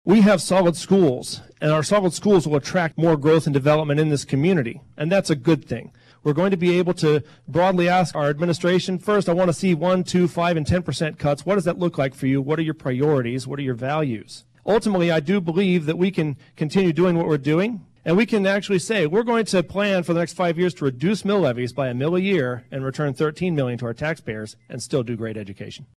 Rising property taxes was a point of emphasis at the USD 383 candidate forum, hosted by the League of Women Voters Saturday.